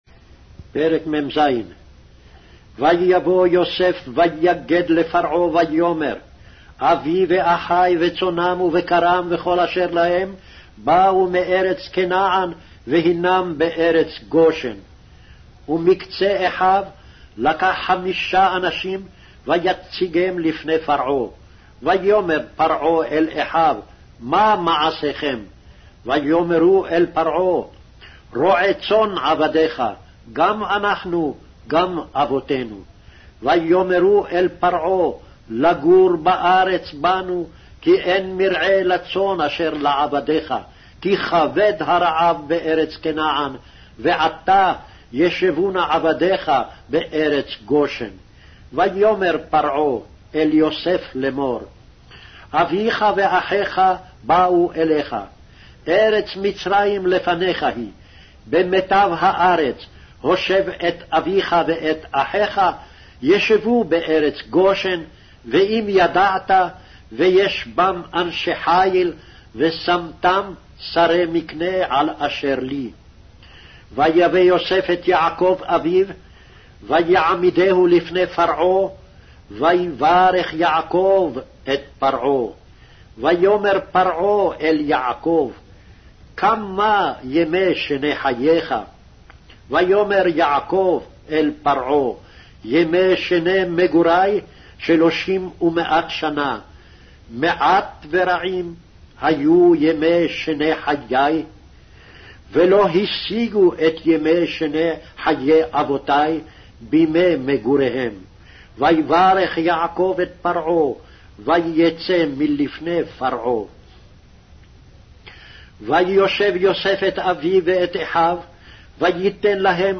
Hebrew Audio Bible - Genesis 29 in Gntbrp bible version